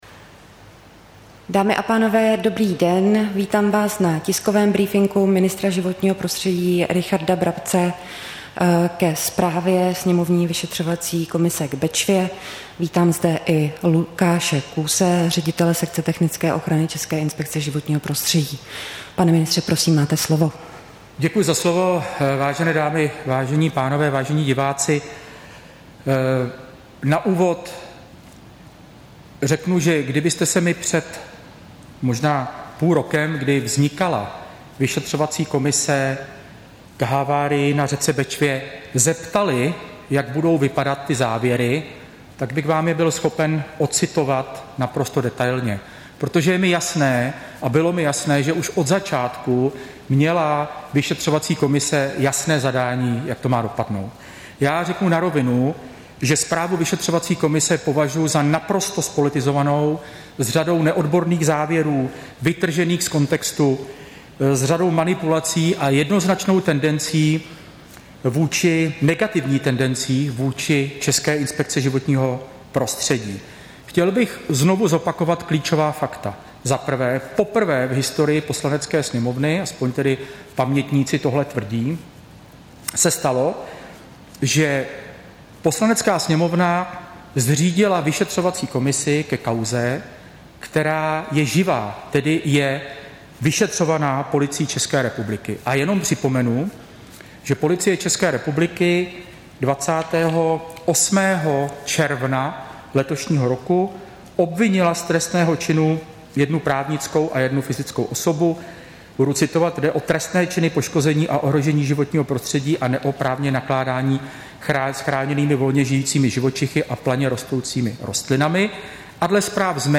Tisková konference ministra životního prostředí ke zprávě o řece Bečvě, 20. září 2021